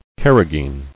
[car·ra·geen]